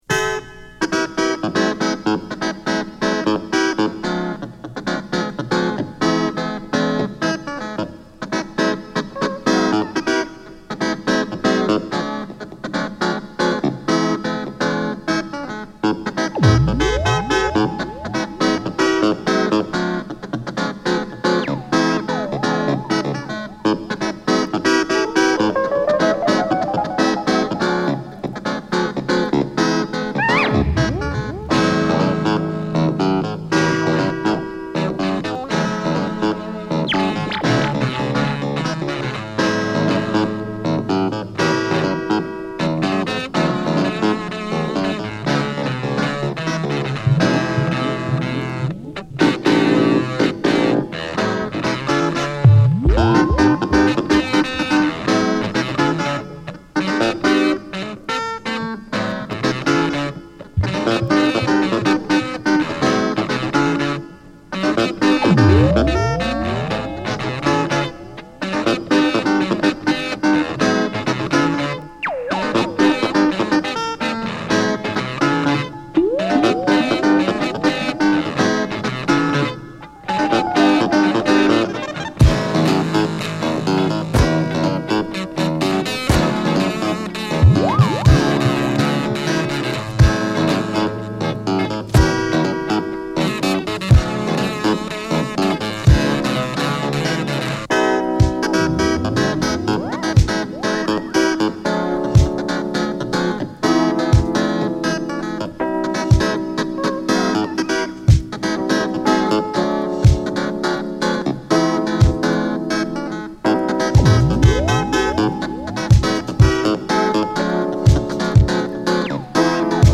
Boogie
Rare Groove , Re-Edit